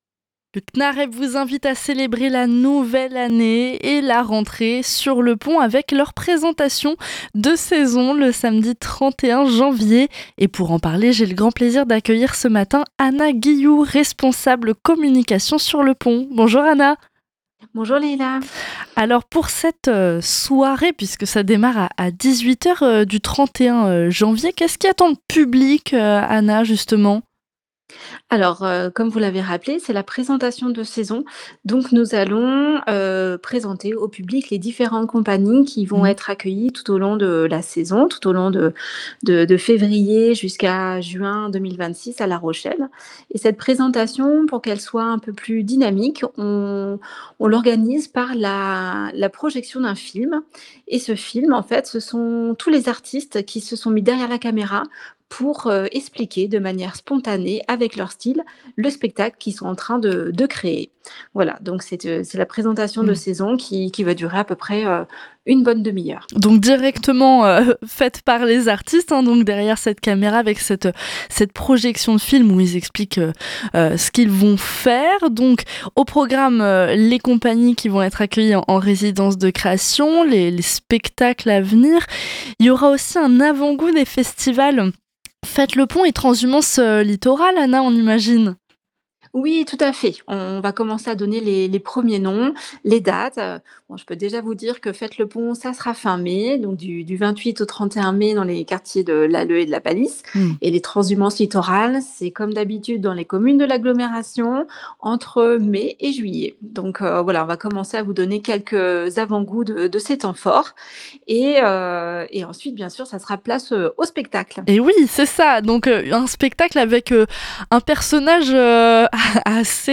L’interview est à retrouver ci-dessous.